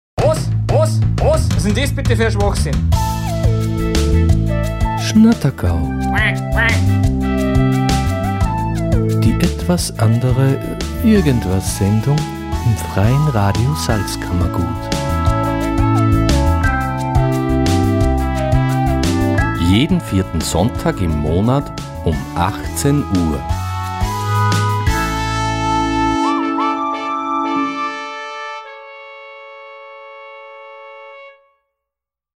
Sendungstrailer
FRS-TRAILER-SCHNATTERGAU-JEDEN-4.-SONNTAG-UM-18-UHR.mp3